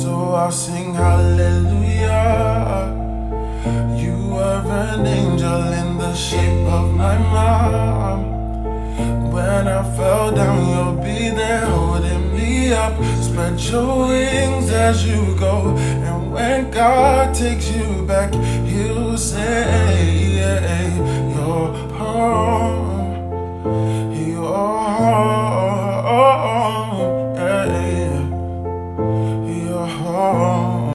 romance/thriller type beat